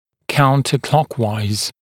[ˌkauntə’klɔkwaɪz][ˌкаунтэ’клокуайз]против часовой стрелки